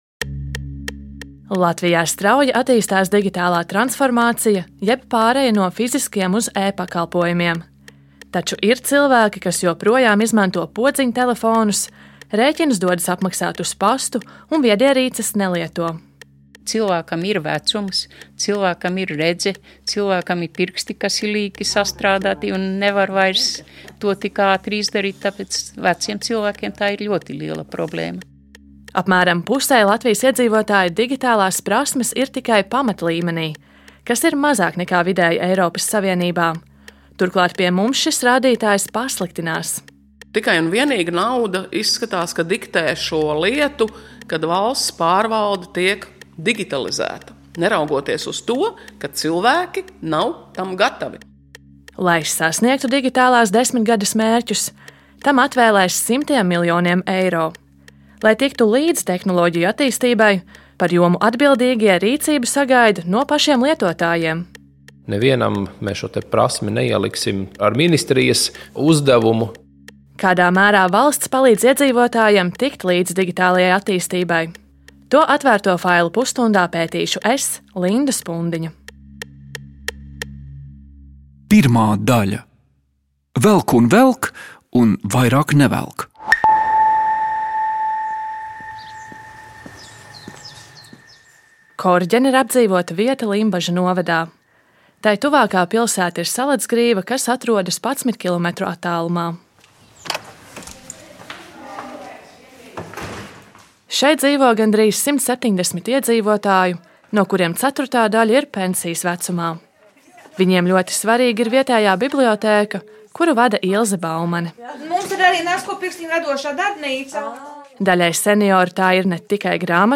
No politikas līdz biznesam, par sociālo dzīvi un krimināldrāmām – pētnieciskais raidījums “Atvērtie faili” iedziļinās mūsu laika svarīgākajos notikumos. Katrs raidījums ir dokumentāls audiostāsts par procesiem un cilvēkiem, kas veido mūsu sabiedrisko dzīvi.